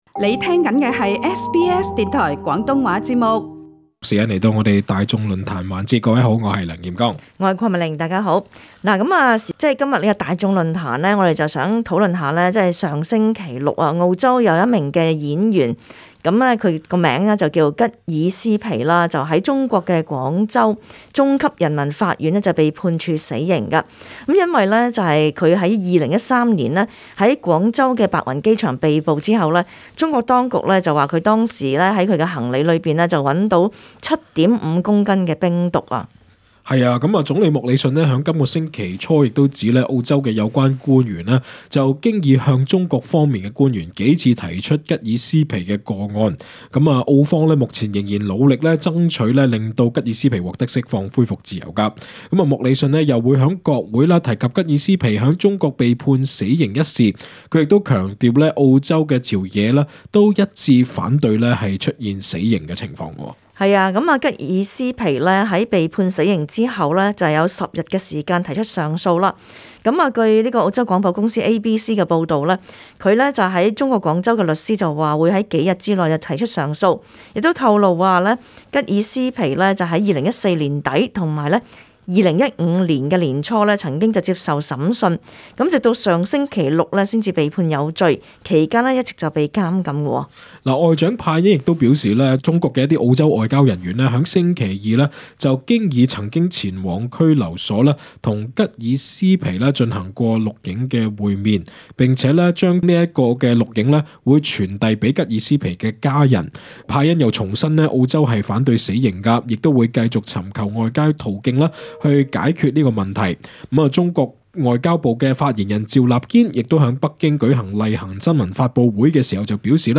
READ MORE 中國同意緩和與印度的緊張邊境關系 【新州水力發電項目】只餘中資公司競投 議員憂慮 【文化360】 素食的故事 本節目內嘉賓及聽眾意見並不代表本台立場 瀏覽更多最新時事資訊，請登上 廣東話節目 Facebook 專頁 ， 或訂閱 廣東話節目Telegram頻道 。